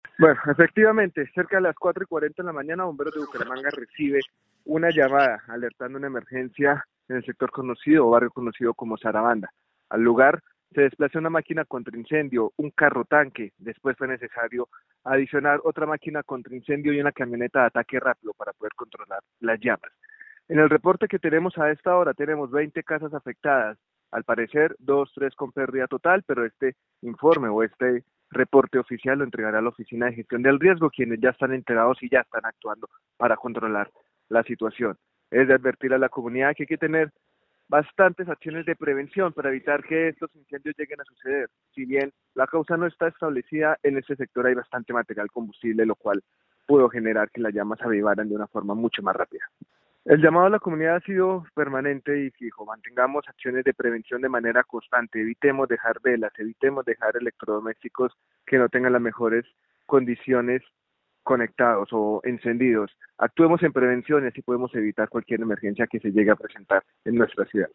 Diego Rodríguez, director de Bomberos de Bucaramanga